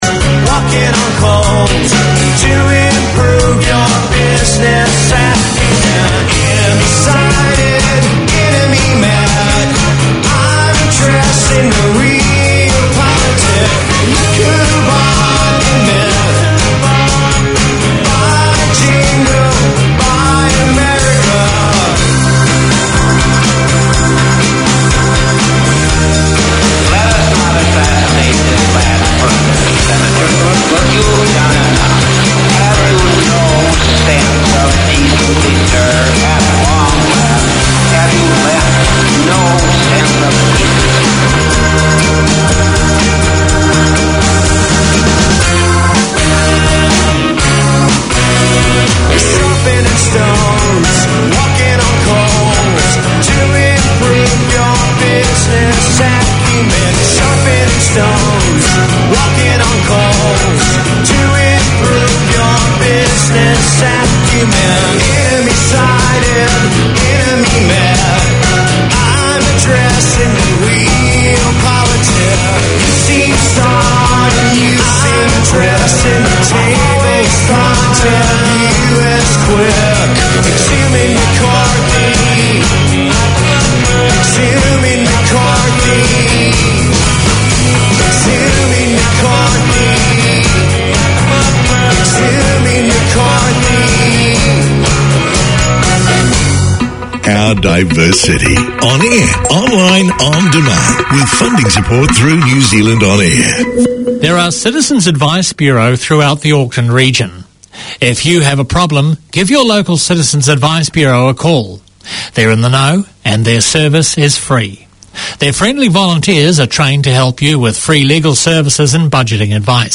Community Access Radio in your language - available for download five minutes after broadcast.
Garden Planet tackles everything from seasonal gardening and garden maintenance, to problem-solving, troubleshooting, and what to plant and when. Tune in for garden goss, community notices and interviews with experts and enthusiasts on all things green or growing.